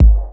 Kick_18_b.wav